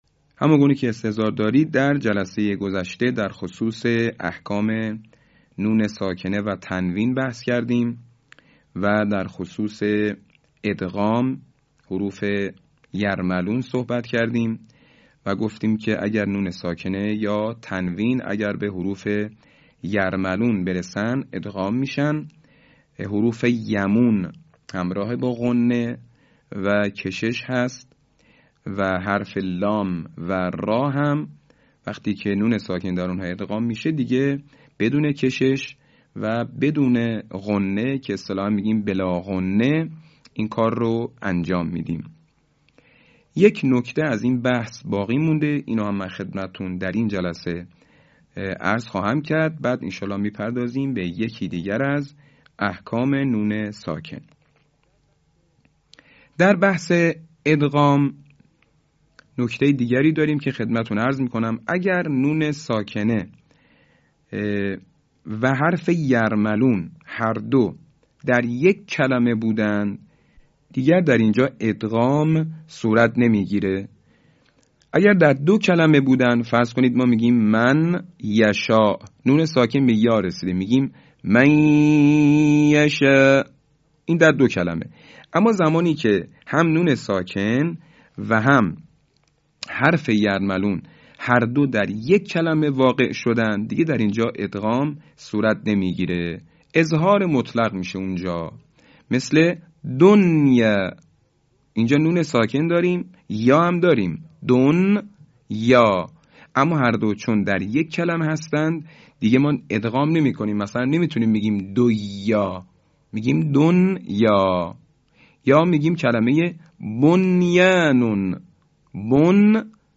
صوت | آموزش احکام ادغام
به همین منظور مجموعه آموزشی شنیداری(صوتی) قرآنی را گردآوری و برای علاقه‌مندان بازنشر می‌کند.